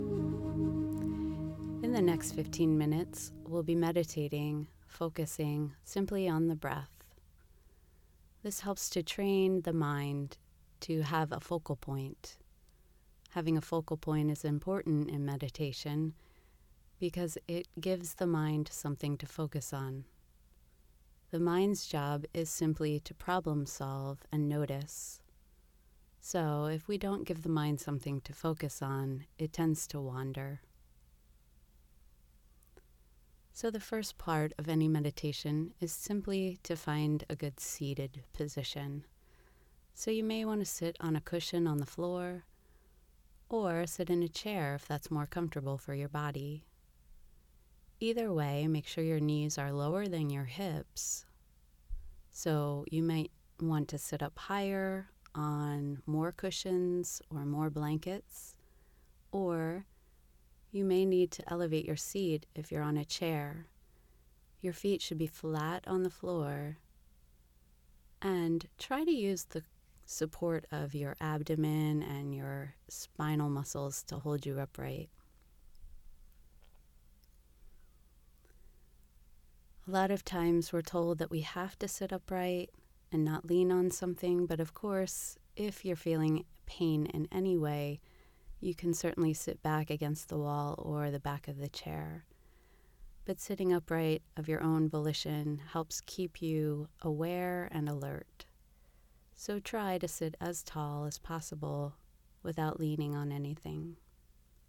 Breath Centered Meditation
breathcenteredmeditation-90sec.mp3